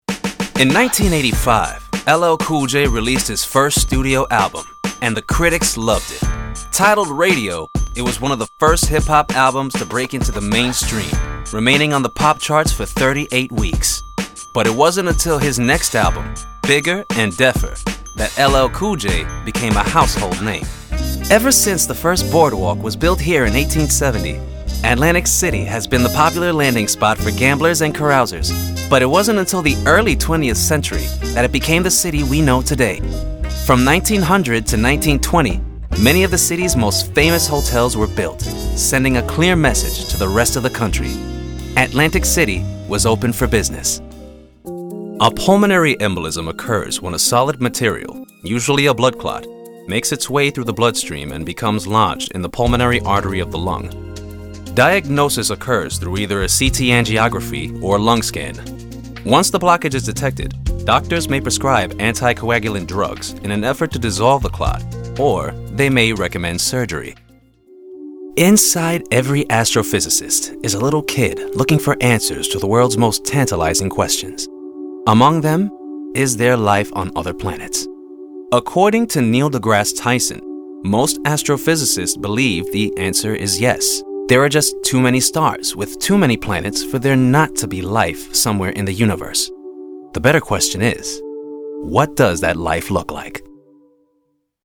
Narration Reel
I use a Deity S-mic 2 Shotgun Mic with a Scarlett SOLO FocusRite preamp, and a padded padded area for sound regulation.